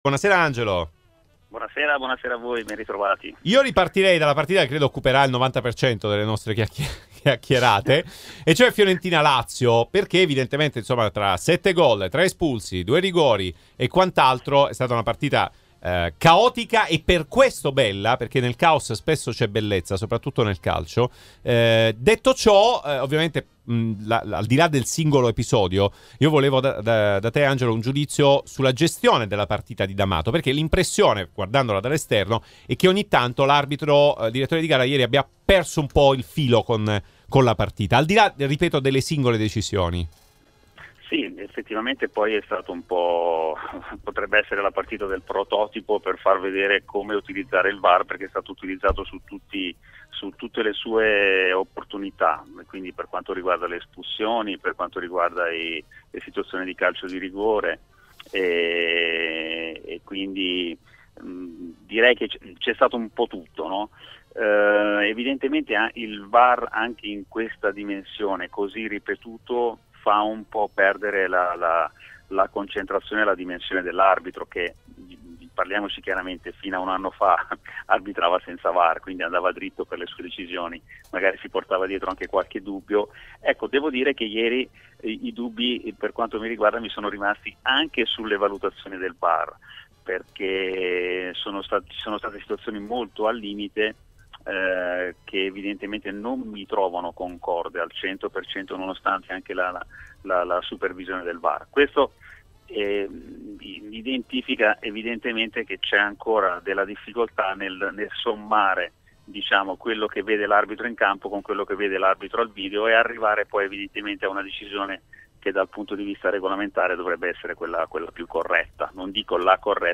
intervento